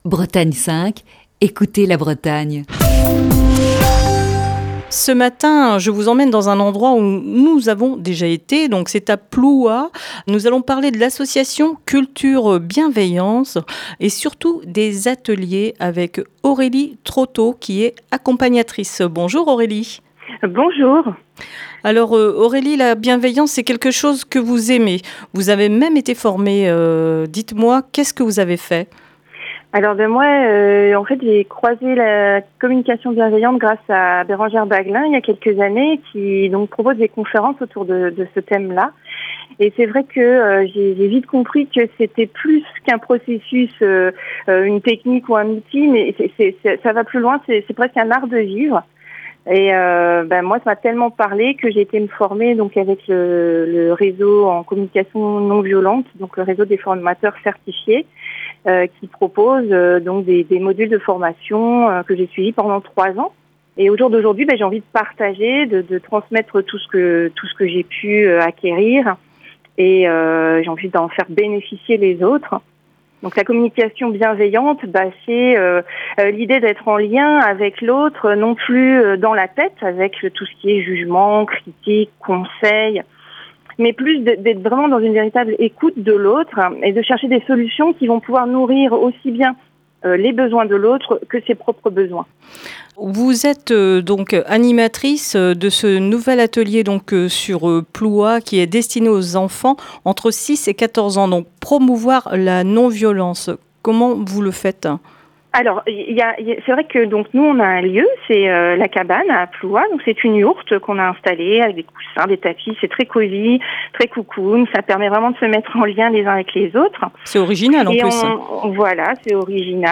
Dans le coup de fil du matin de ce jeudi